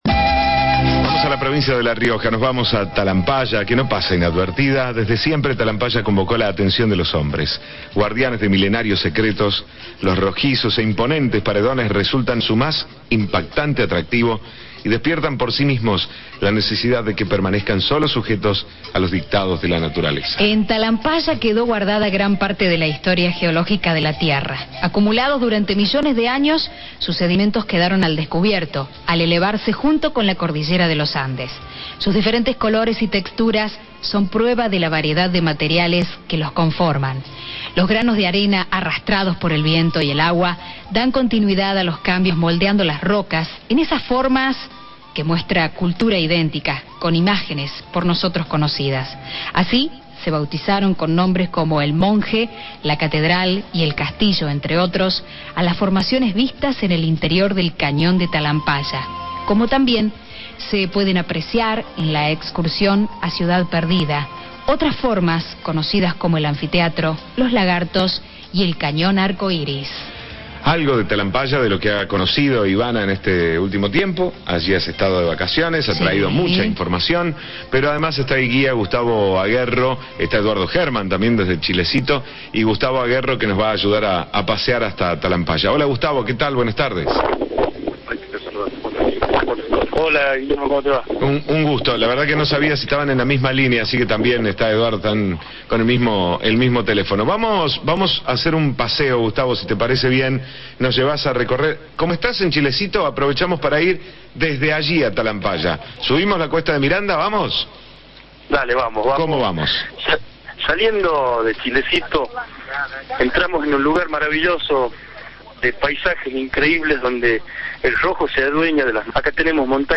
El informe de radio Cadena 3 Argentina